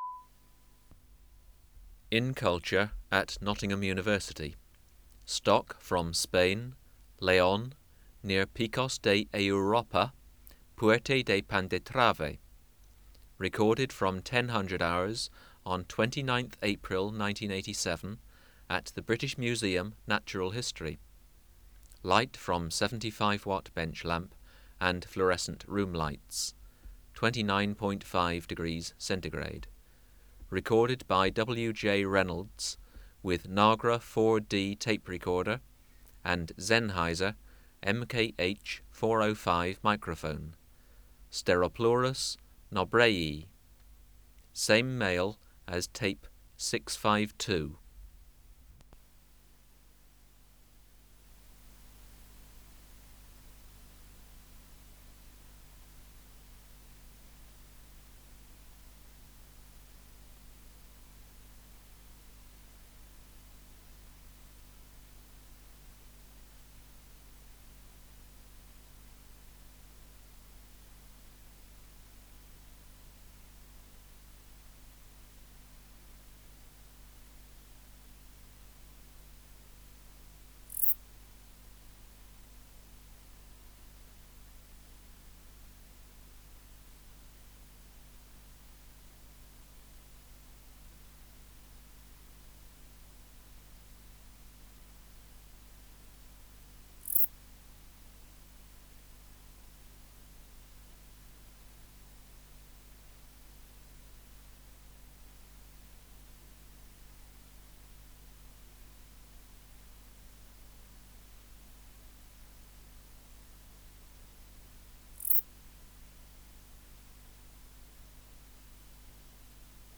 Recording Location: BMNH Acoustic Laboratory
Reference Signal: 1 kHz for 10 s
Substrate/Cage: In large recording cage
Microphone & Power Supply: Sennheiser MKH 405 Distance from Subject (cm): 20 Filter: Low Pass, 24 dB per octave, corner frequency 20 kHZ